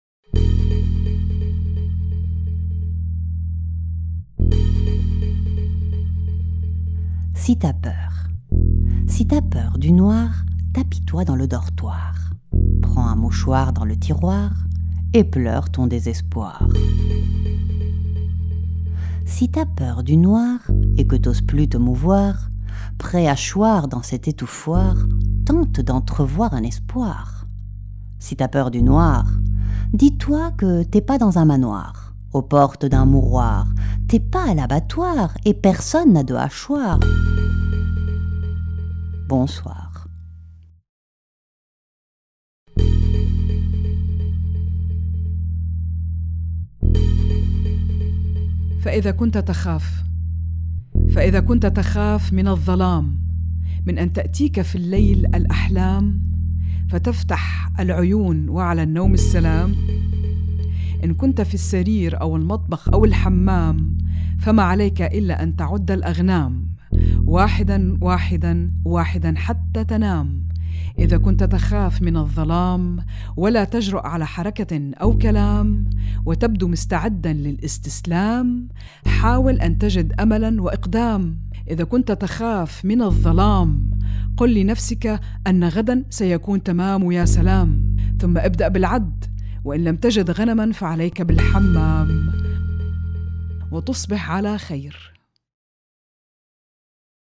enregistrement, création sonore et mixage
lecture en français
lecture en arabe